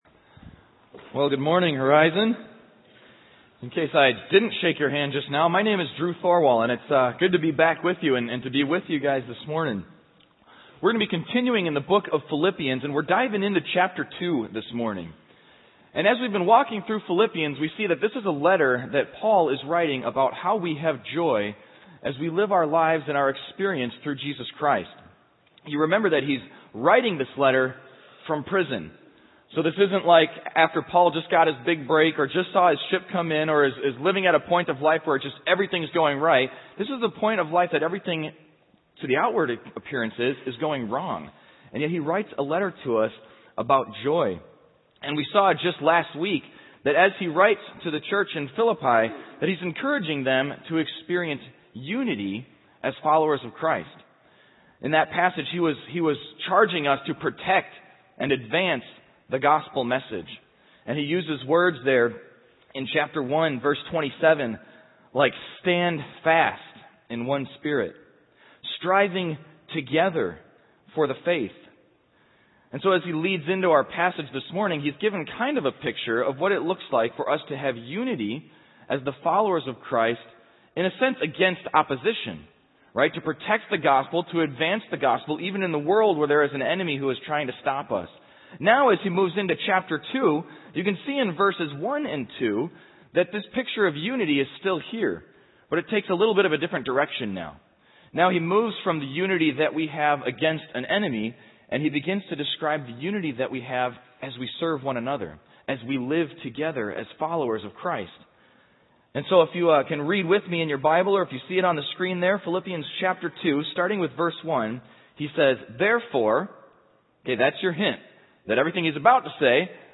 Equipping Service / Receive, Rejoice, Regardless / Fleshing Out Faith